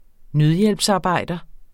Udtale [ ˈnøðjεlbs- ]